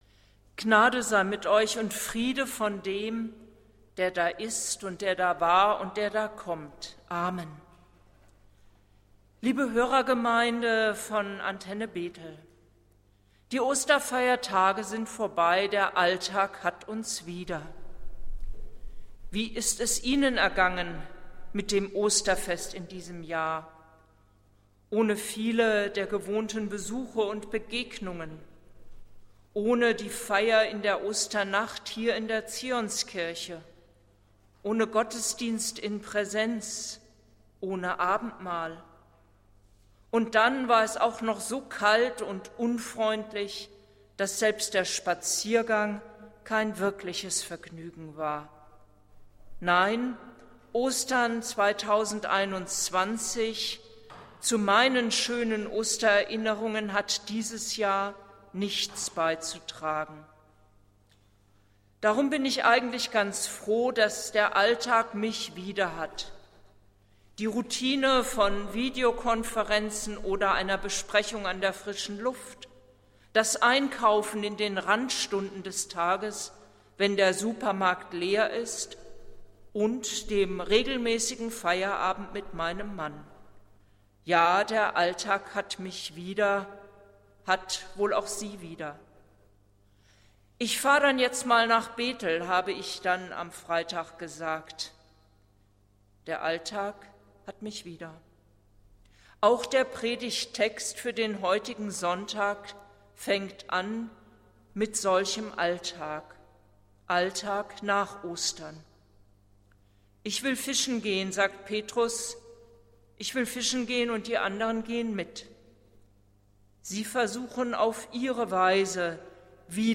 Predigt des Gottesdienstes aus der Zionskirche vom Sonntag, den 11.04.2021